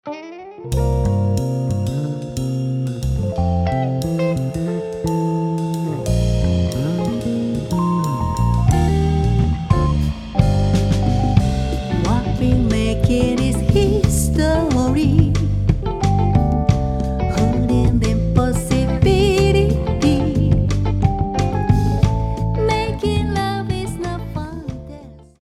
70年代ソウルミュージックの名曲をカヴァーしたリスペクトアルバム